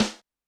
Drums_K4(23).wav